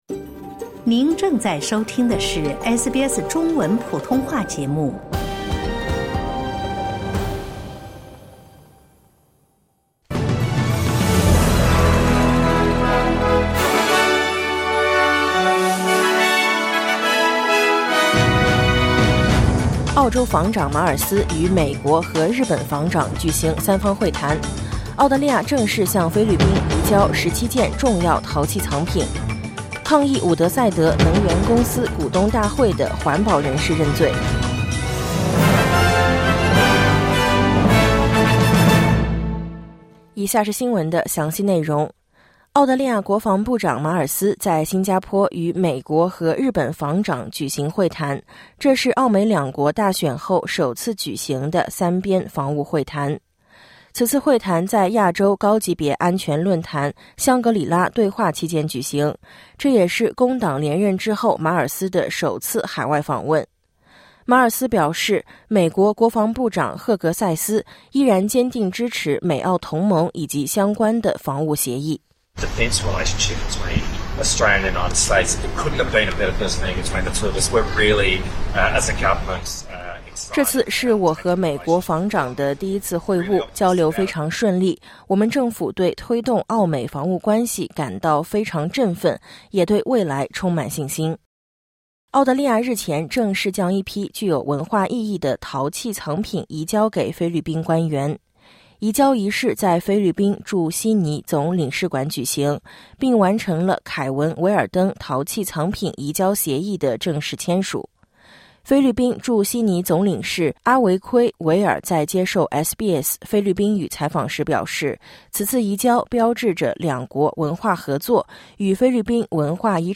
SBS早新闻（2025年5月31日）